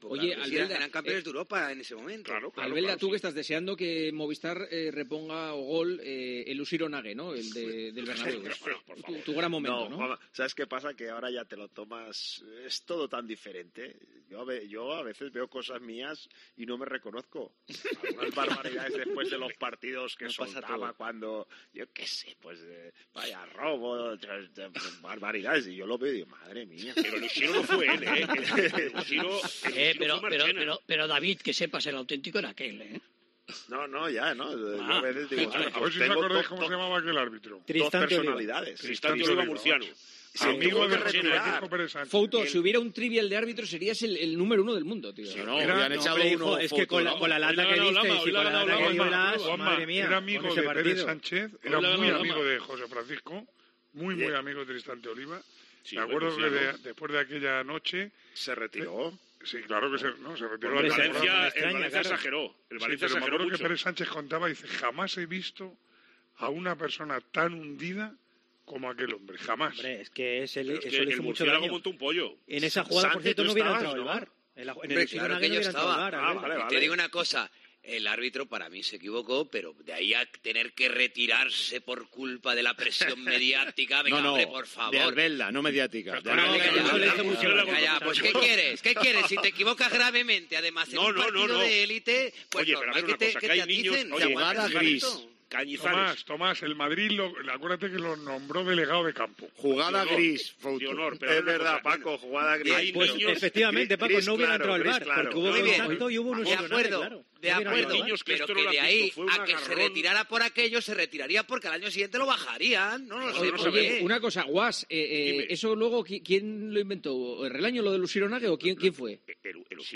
A veces en un tono más elevado, agolpándose a tu alrededor, zumbándote los oídos.